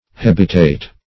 hebetate - definition of hebetate - synonyms, pronunciation, spelling from Free Dictionary
Hebetate \Heb"e*tate\, v. t. [imp. & p. p. Hebetated; p. pr. &